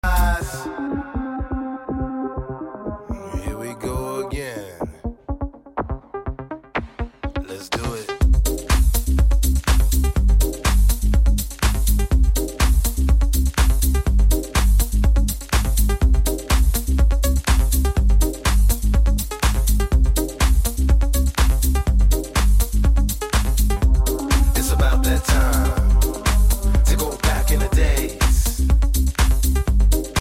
open-air